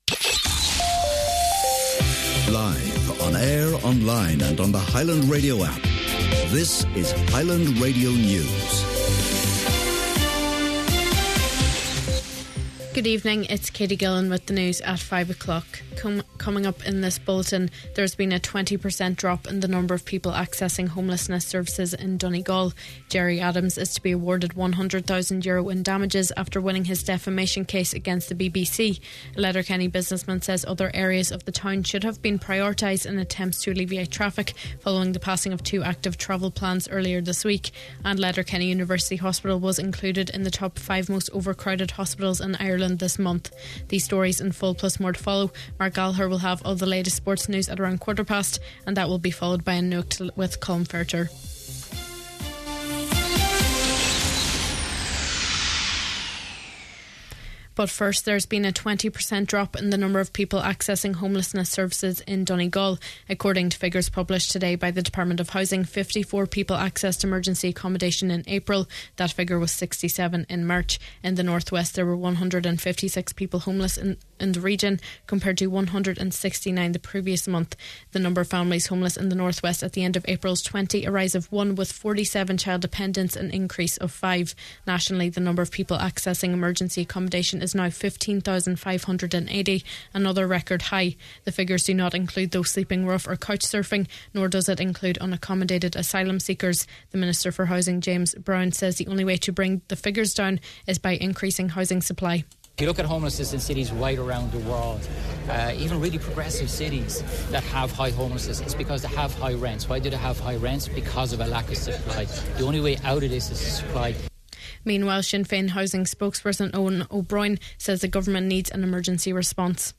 Main Evening News, Sport, an Nuacht and Obituaries – Friday May 30th